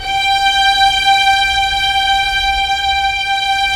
Index of /90_sSampleCDs/Roland LCDP13 String Sections/STR_Symphonic/STR_Symph. %wh